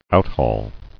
[out·haul]